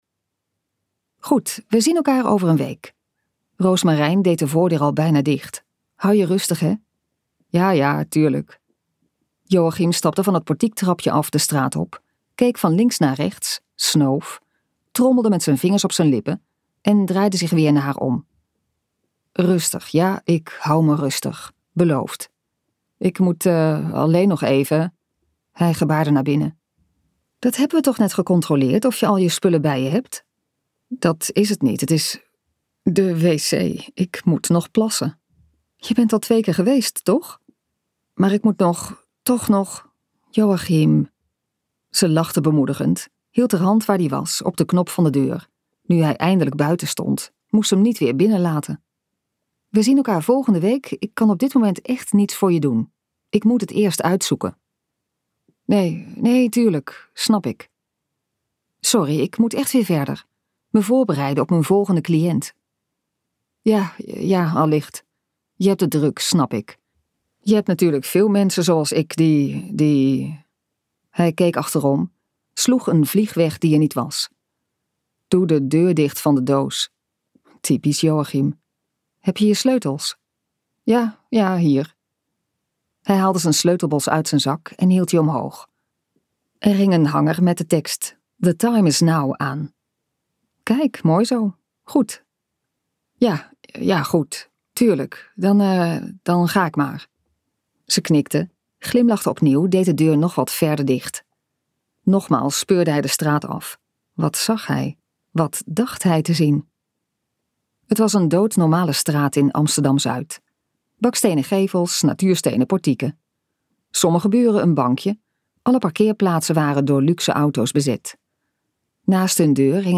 Ambo|Anthos uitgevers - Help me luisterboek